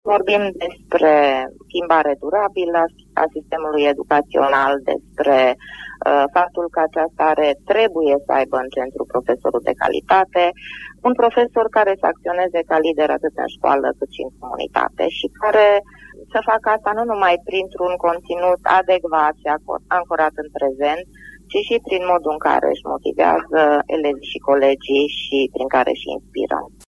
extras din emisiunea Pulsul Zilei